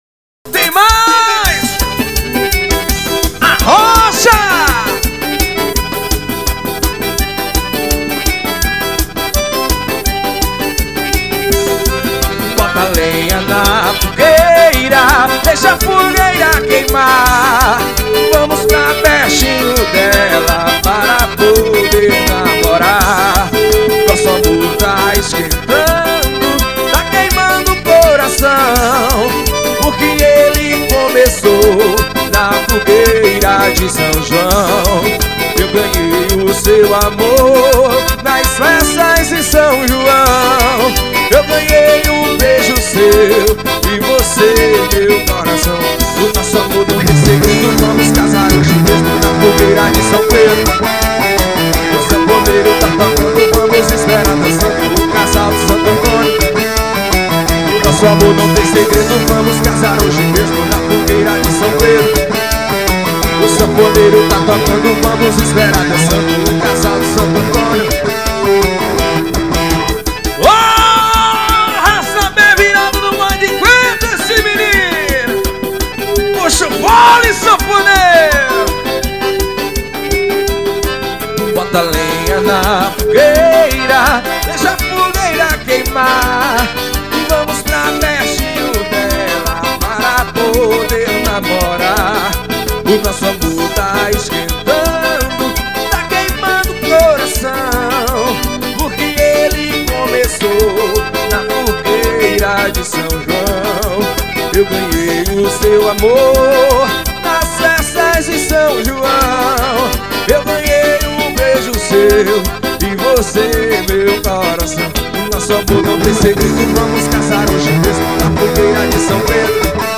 Forró.